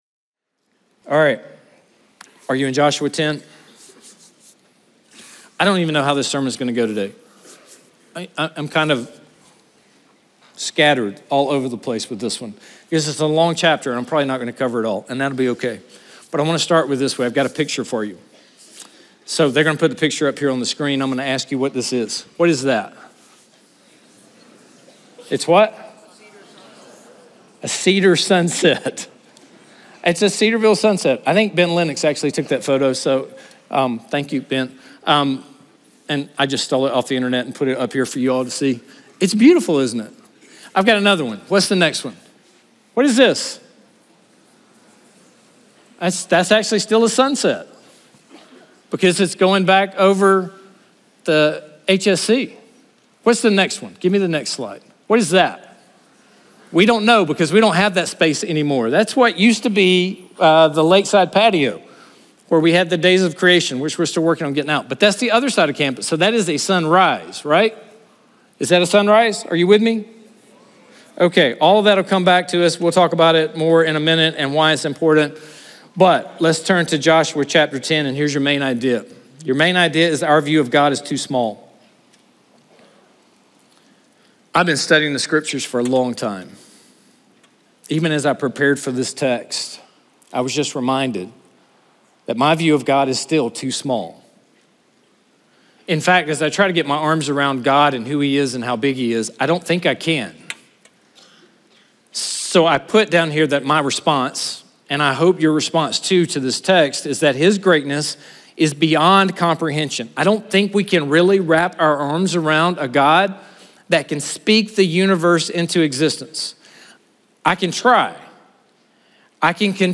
chapel speaker